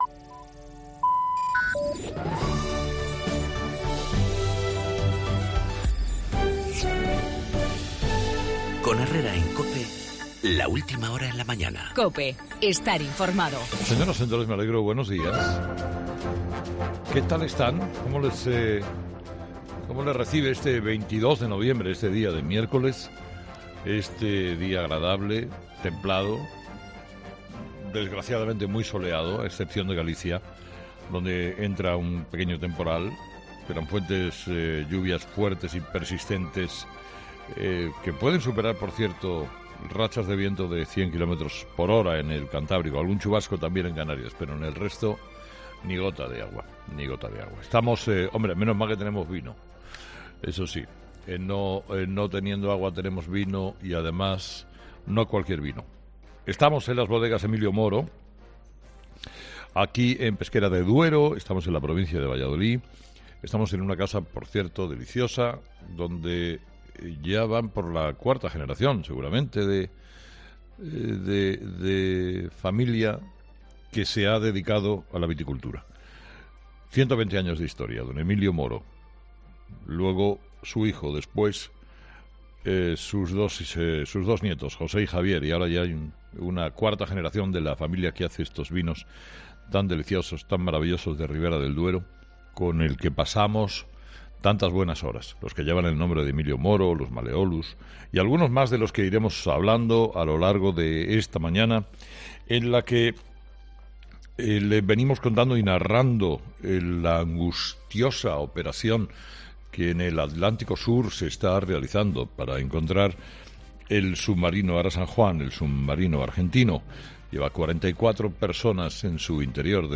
Monólogo de las 8 de Herrera
'Herrera a las 8' desde Bodegas Emilio Moro: “Cuando no gobiernas, es fácil oponerse como Ciudadanos al cupo vasco”